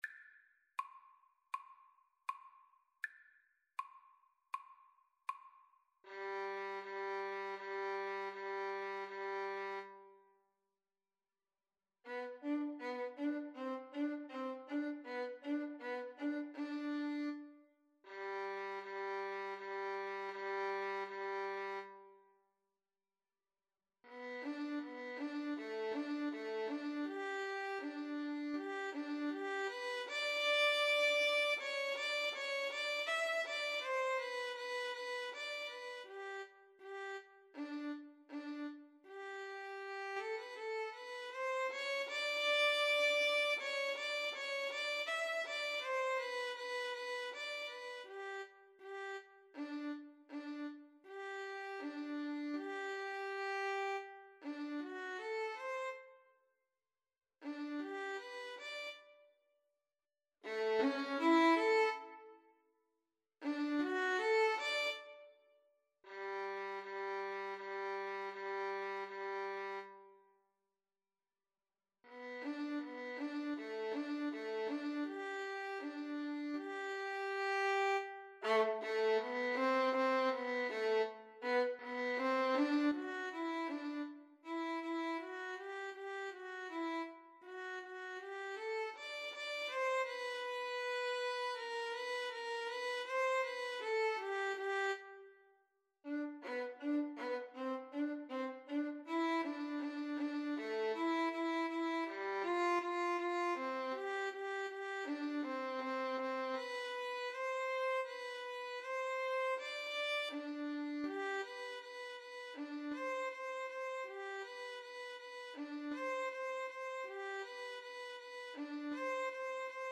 Free Sheet music for Violin Duet
Violin 1Violin 2
G major (Sounding Pitch) (View more G major Music for Violin Duet )
Andante
4/4 (View more 4/4 Music)
Classical (View more Classical Violin Duet Music)